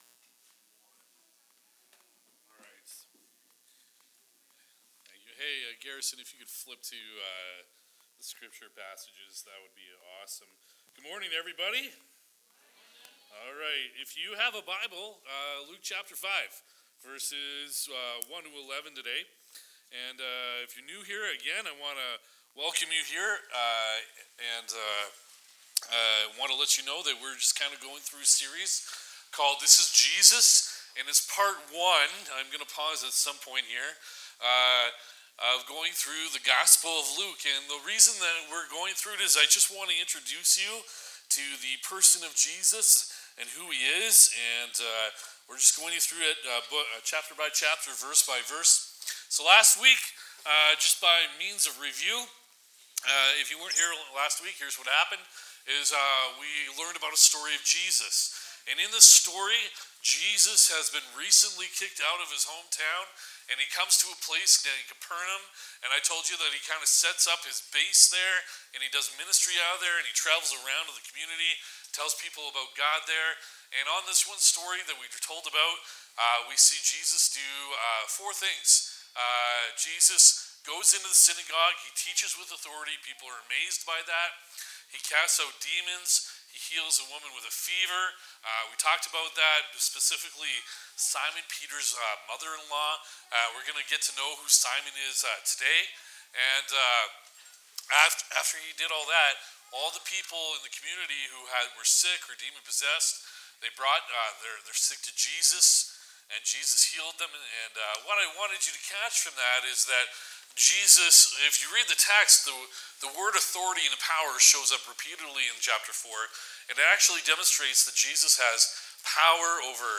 Download Download From this series Current Sermon What Kind of People Does Jesus Use? This Is Jesus: The Gospel of Luke - Part 1